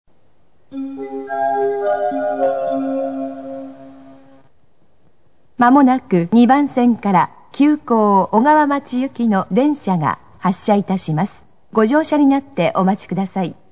発車放送・女性（急行・小川町） MD